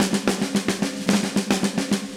AM_MiliSnareC_110-03.wav